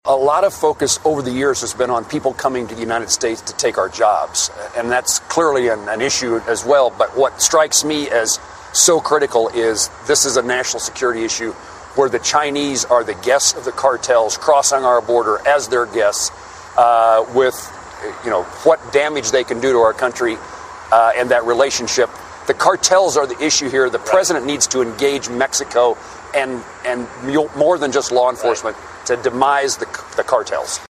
Audio from Fox News.